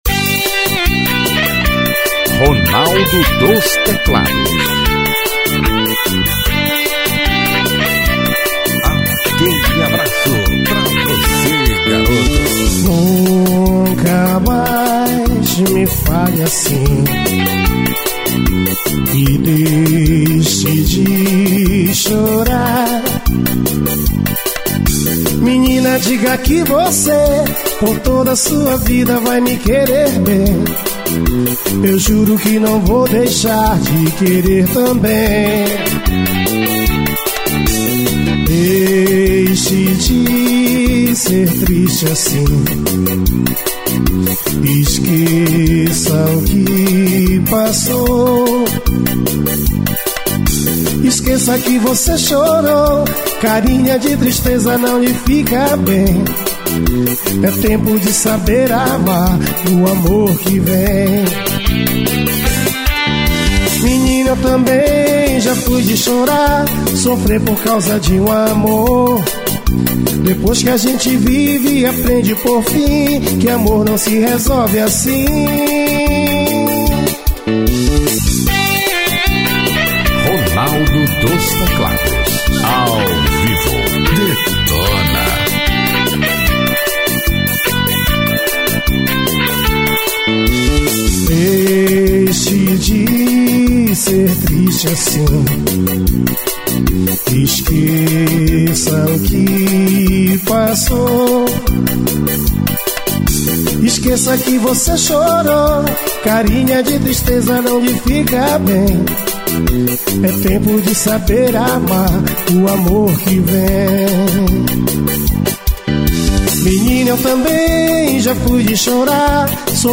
AO VIVO BAR.